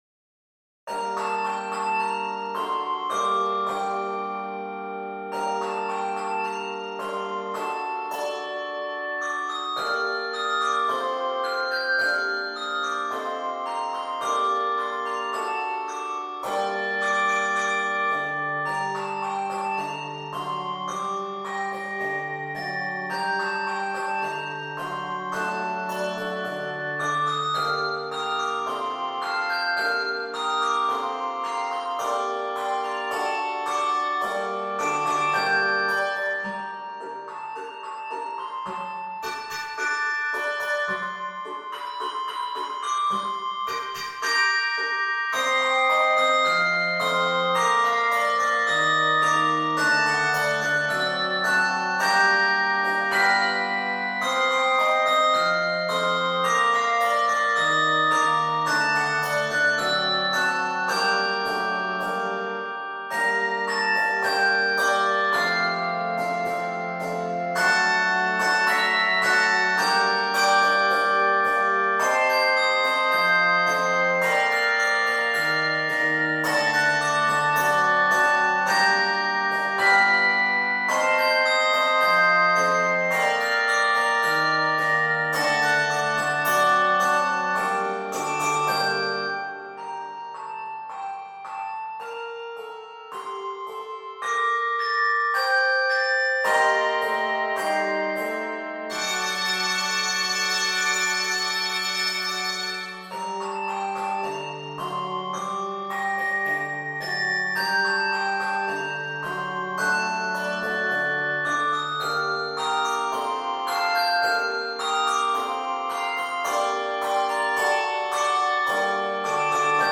lively, dance-like original composition for handbells
Composed in g minor, this piece is 69 measures.